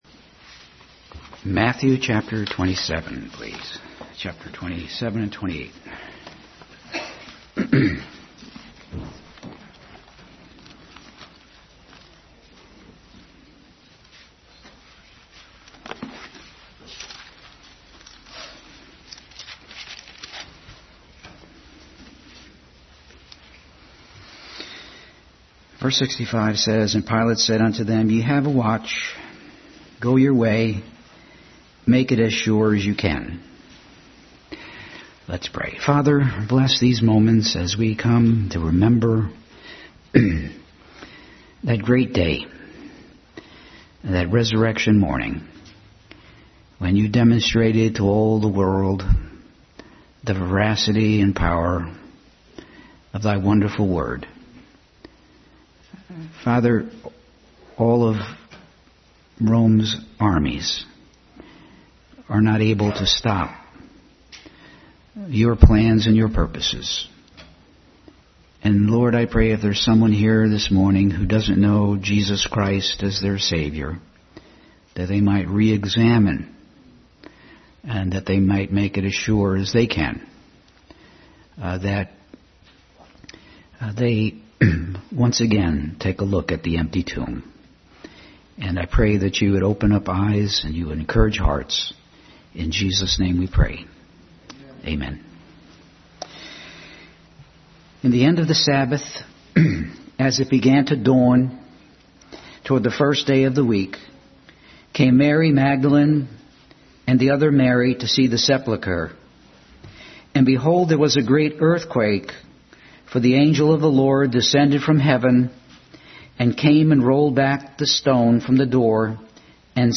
Matthew 27-28 Service Type: Family Bible Hour Bible Text